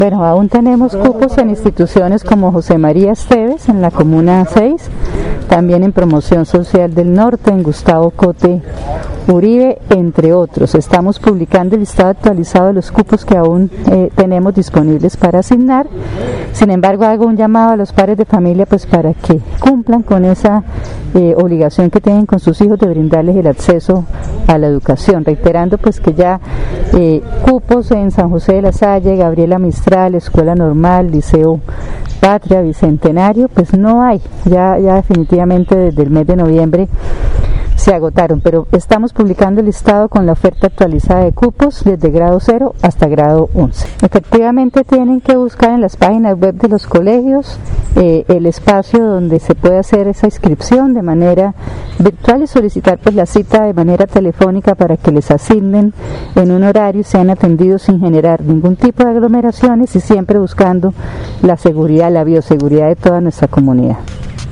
Descargue audio: Ana Leonor Rueda, secretaria de Educación